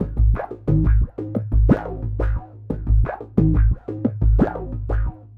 Index of /90_sSampleCDs/Spectrasonic Distorted Reality 2/Partition A/03 80-89 BPM